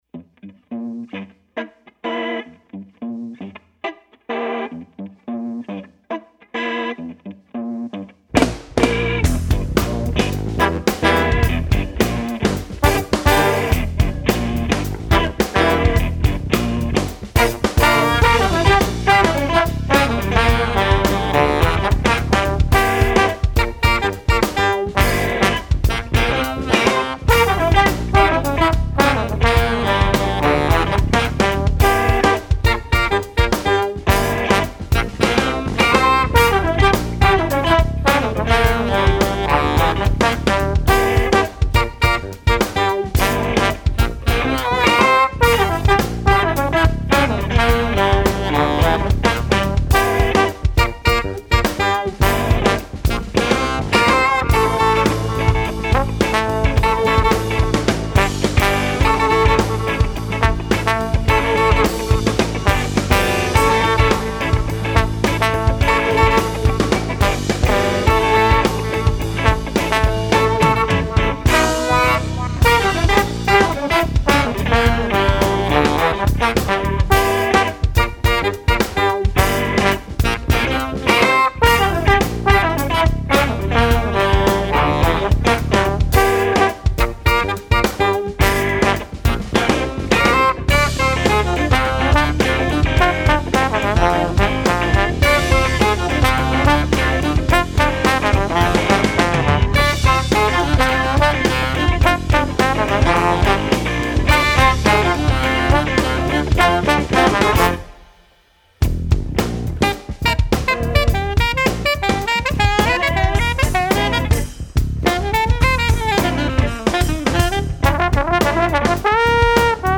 Style-bending, out-funk acolytes
trombone
alto sax
drums